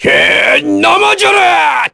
Phillop-Vox_Skill1_kr.wav